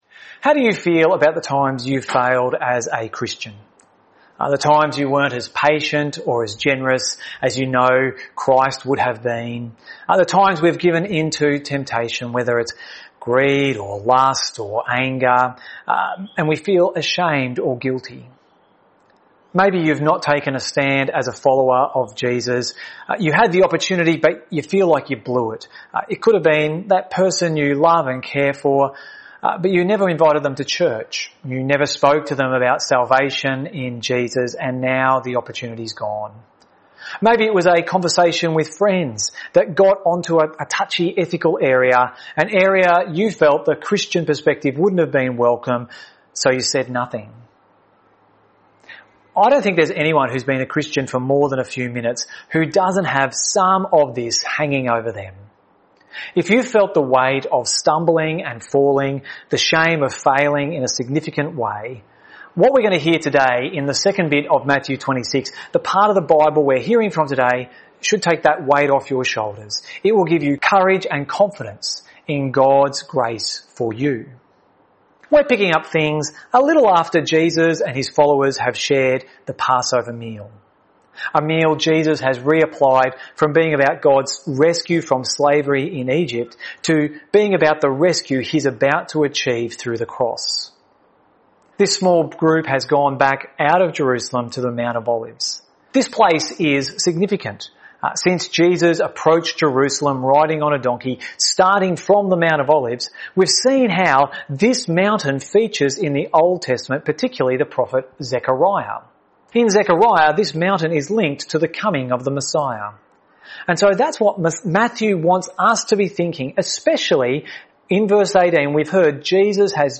This sermon was pre-recorded.